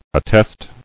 [at·test]